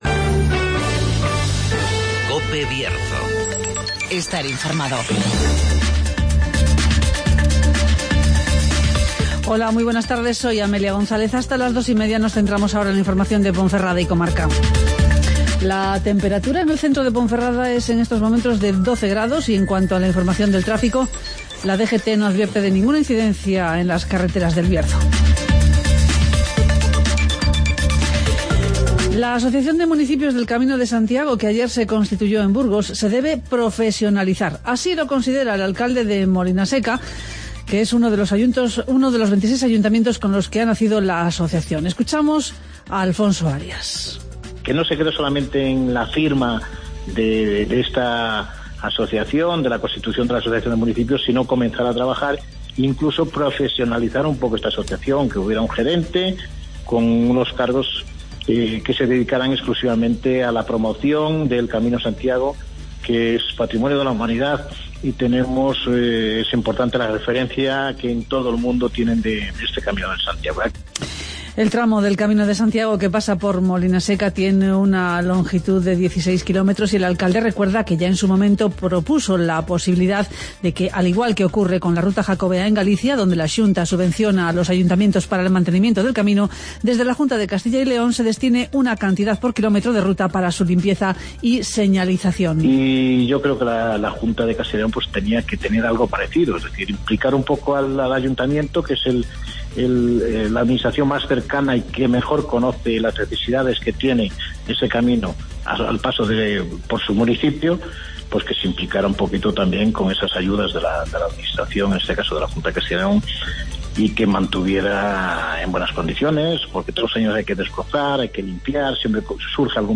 Escucha las noticias de Ponferrada y comarca en el Informativo Mediodía de COPE Bierzo